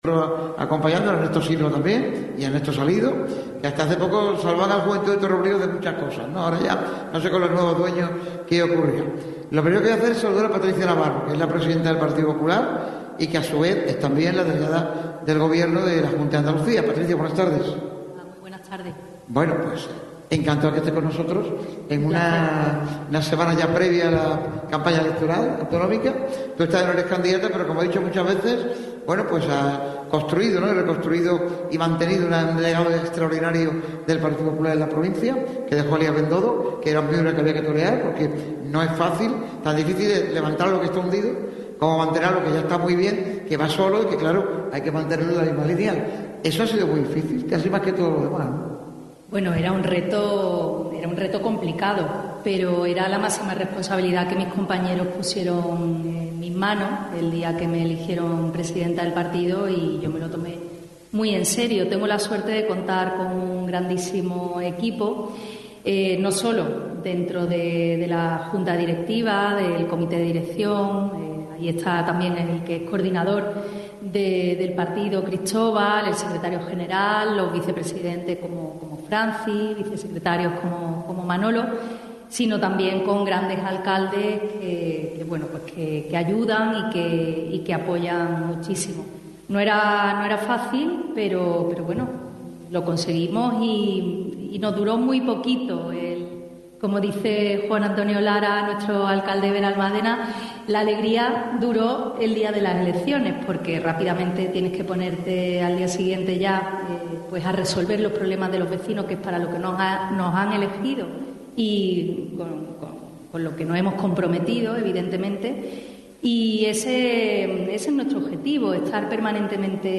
El mandatario popular, que ganó las elecciones de 2023 y gobierna con mayoría absoluta en el municipio, se ha pasado por el programa especial realizado por la radio del deporte este miércoles 22 de abril en el auditorio Edgar Neville de la Diputación de Málaga.